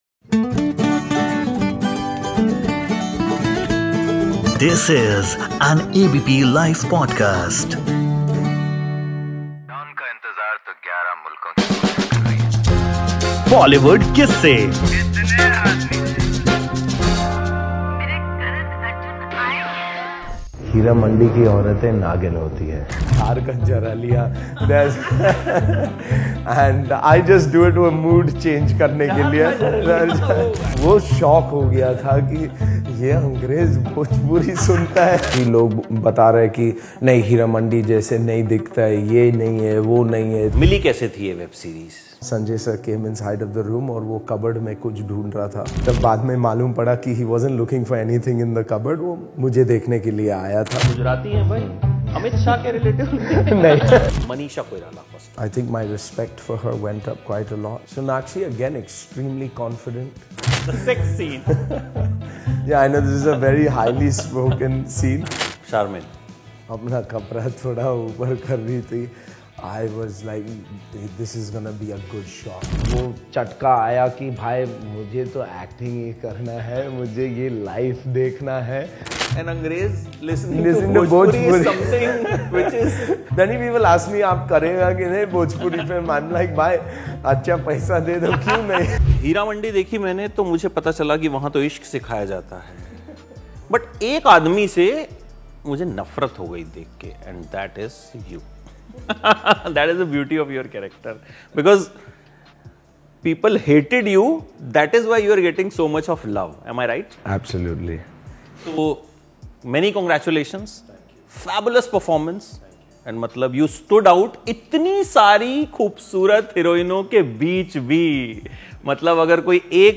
इन इस साक्षात्कार में, कार्टराईट की भूमिका निभाने वाले जेसन शाह हीरामंडी में अपनी भूमिका के बारे में बात करते हैं, यह कैसे पेश किया गया था?